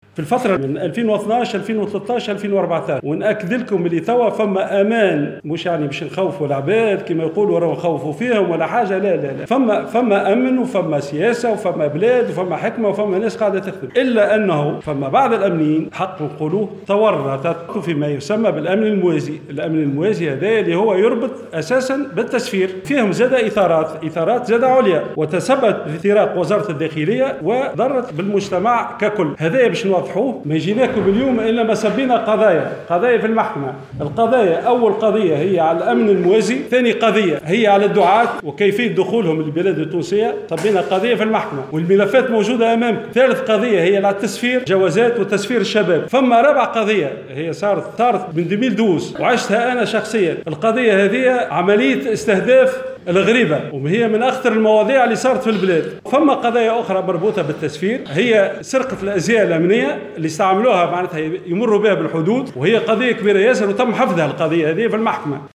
وأكد في تصريح لمراسلة "الجوهرة أف أم" على هامش جلسة استماع من قبل لجنة التحقيق حول شبكات التجنيد أن النقابة رفعت قضية في الغرض للكشف عن الأطراف التي سهّلت دخول 72 داعية، من بينهم 9 دعاة ممنوعين من الدخول إلى التراب التونسي.